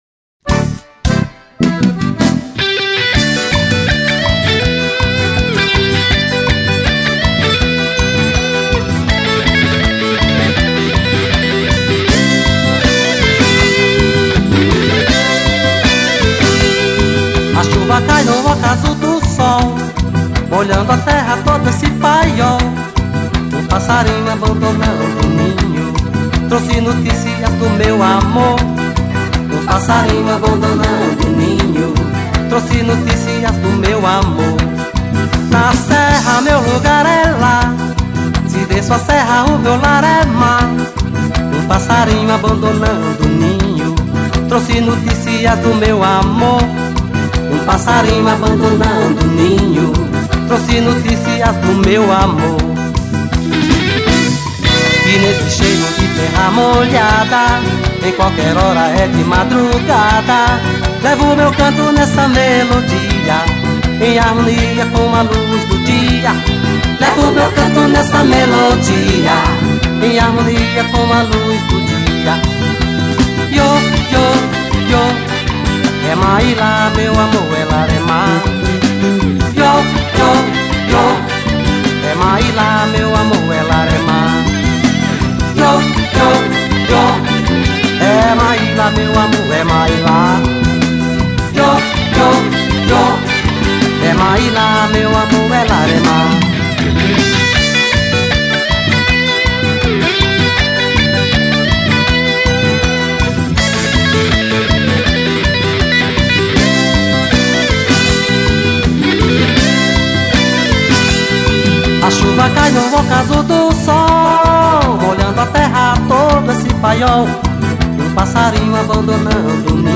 Forró